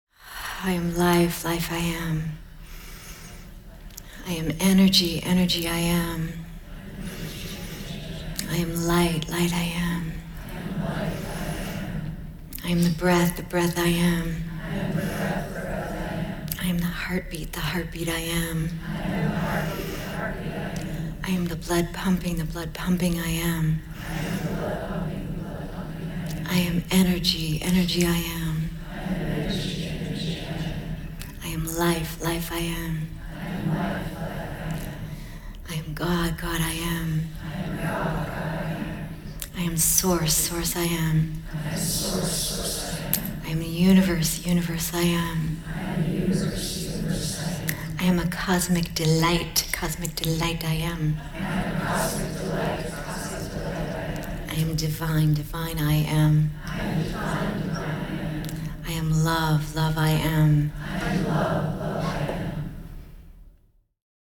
Recorded over the course of the March 2017 "Feel Good Now" 5-day Soul Convergence
This track also includes honoring the anchoring of the Divine feminine on the planet, the chanting of Jai Ma, which is Sanskrit for “Victory of the Divine Mother,” as well as the chanting of Om.